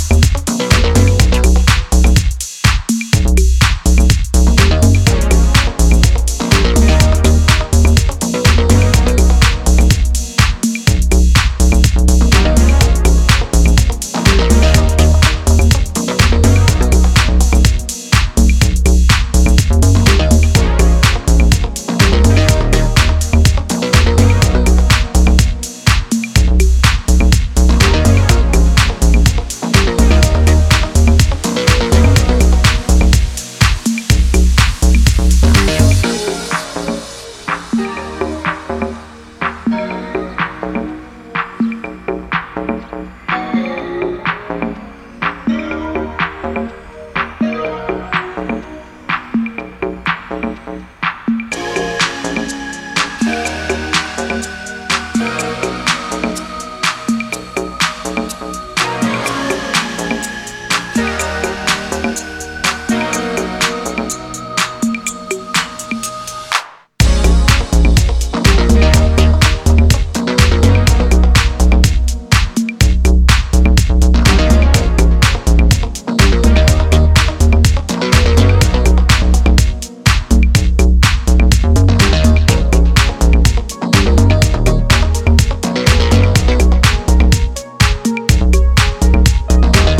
音質も良好。
ジャンル(スタイル) DEEP HOUSE / NU DISCO